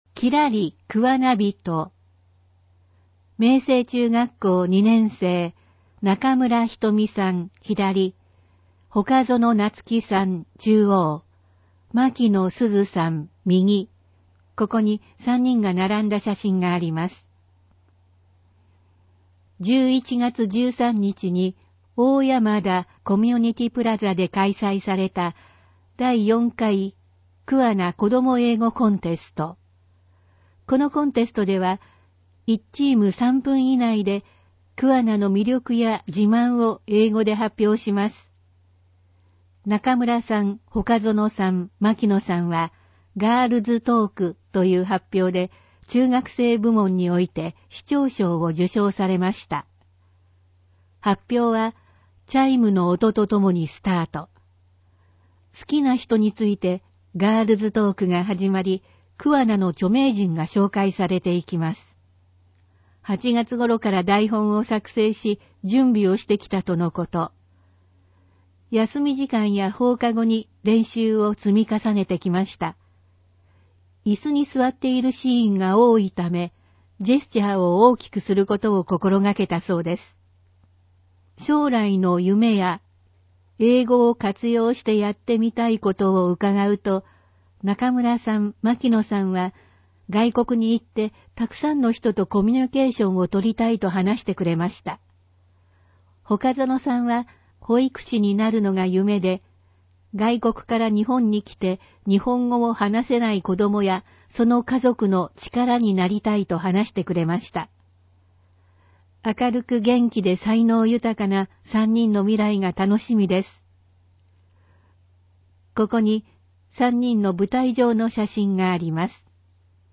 なお、「声の広報くわな」は桑名市社会福祉協議会のボランティアグループ「桑名録音奉仕の会」の協力で制作しています。